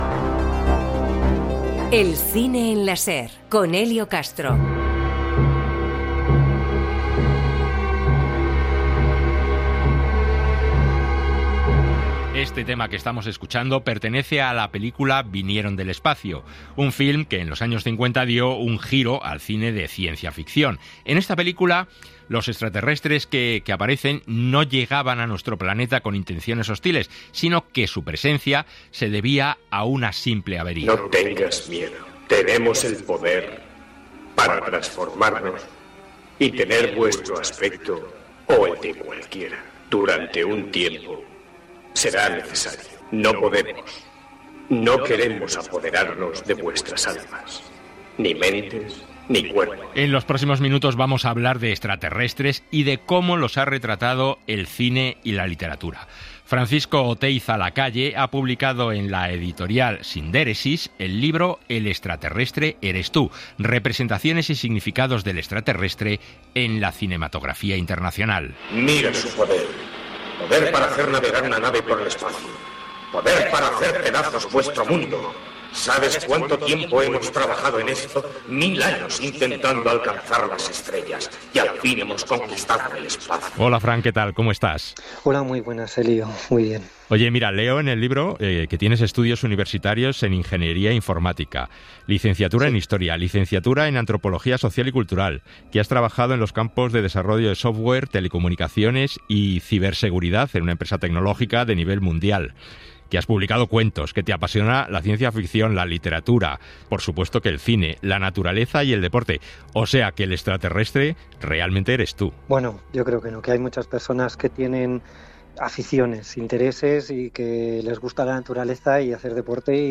Entrevista en cadena SER
entrevista-en-cadena-ser.mp3